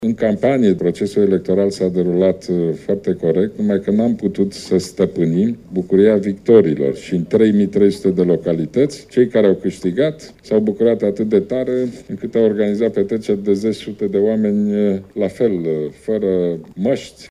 Premierul, prezent la Conferința Națională a Industriei Ospitalității, a spus câte astfel situații au fost raportate la nivelul întregii țări.